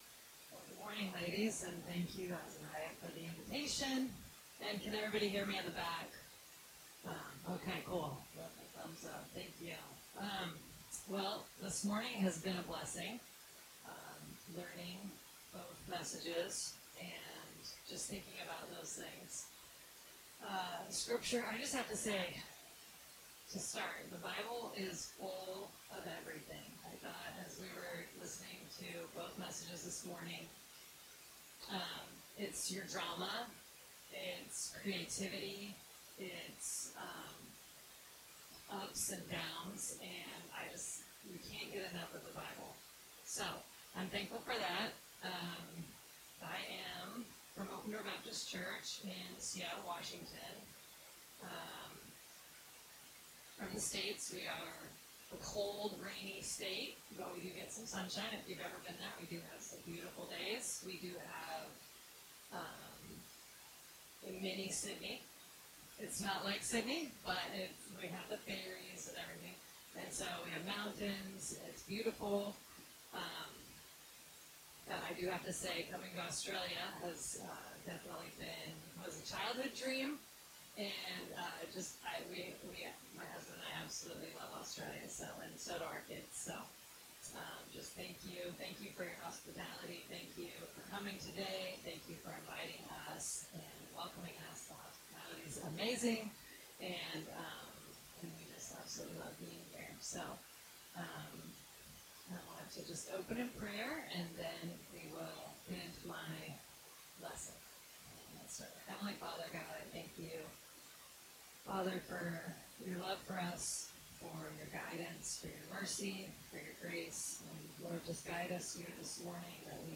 Wed 3rd Session - Ladies Selah Conference 2026